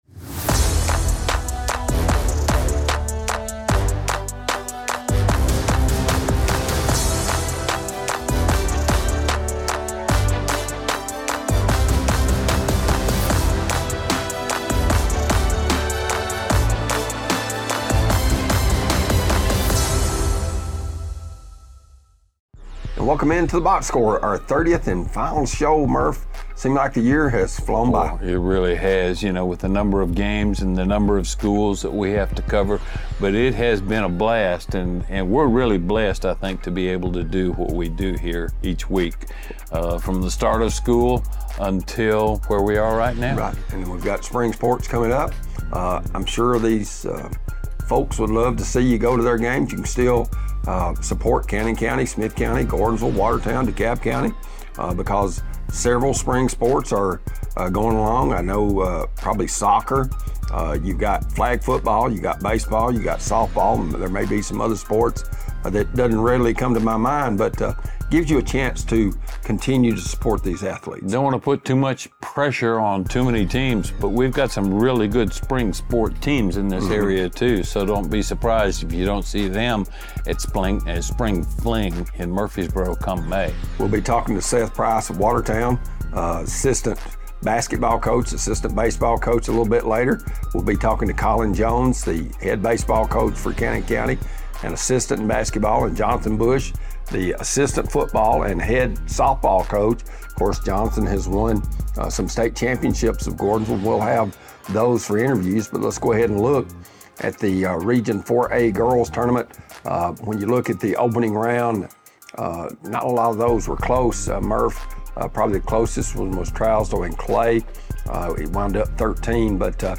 weekly sports talk show